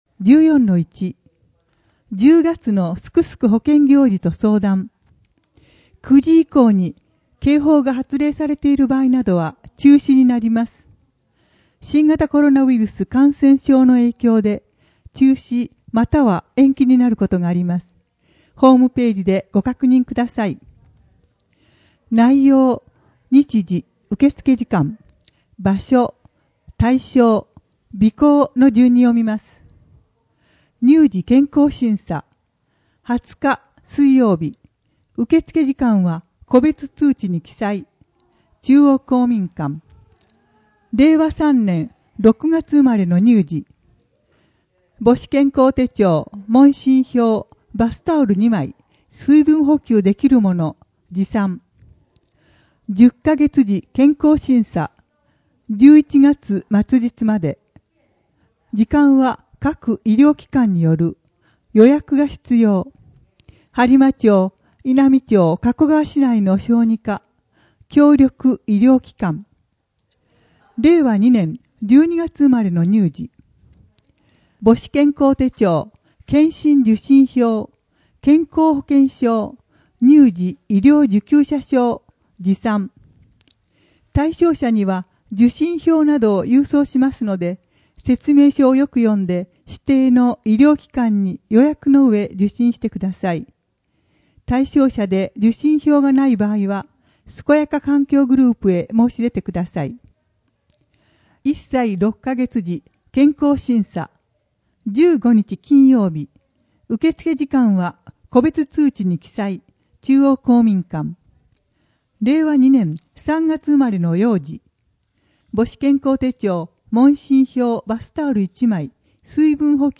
声の「広報はりま」10月号
声の「広報はりま」はボランティアグループ「のぎく」のご協力により作成されています。